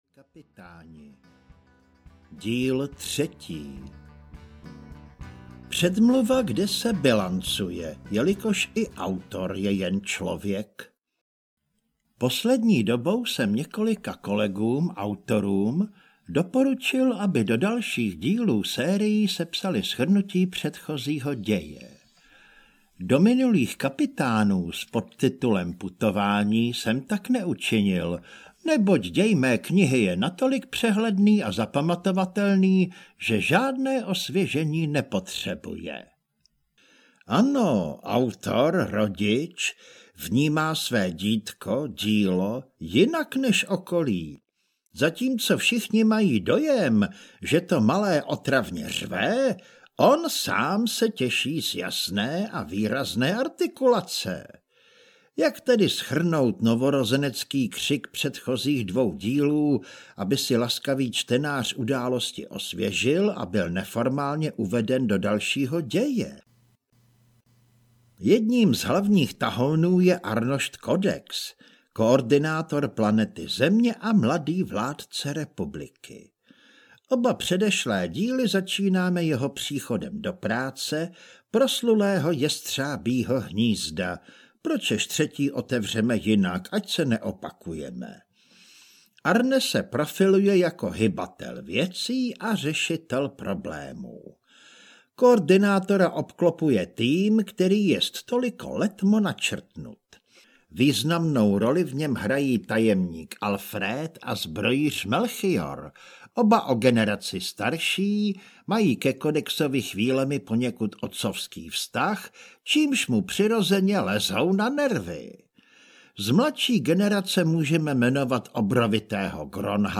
Ukázka z knihy
tri-kapitani-3-korzarska-ctverylka-audiokniha